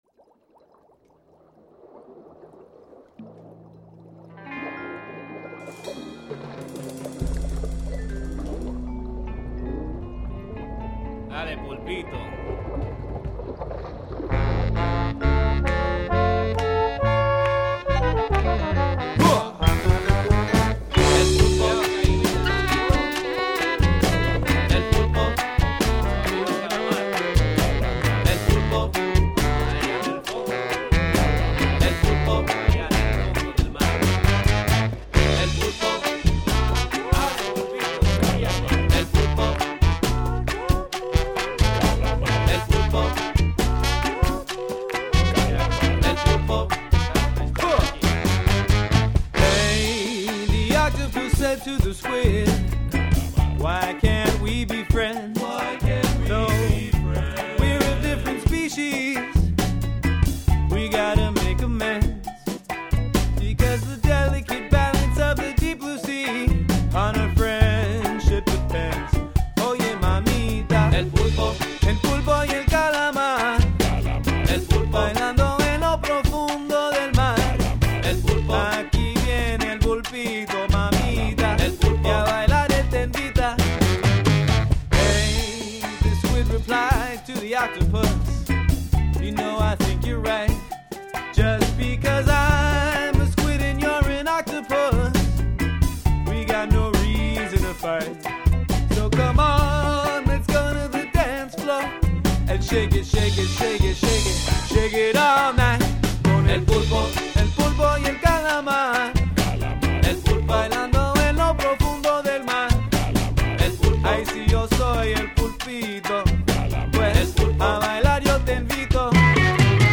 ki izvaja latino in karibske dance ritme.